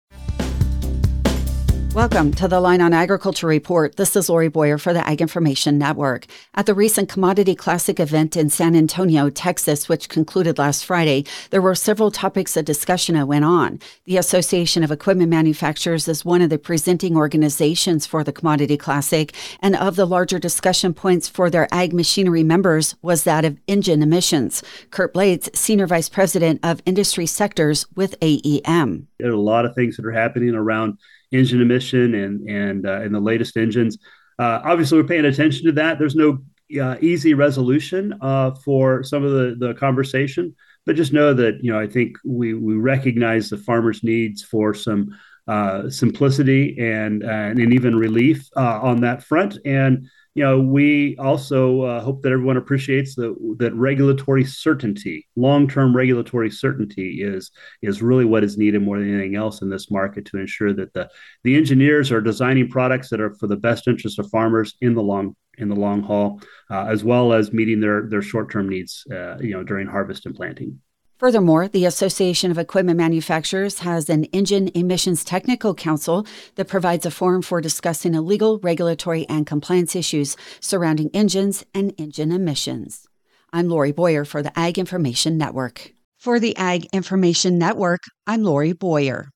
Reporter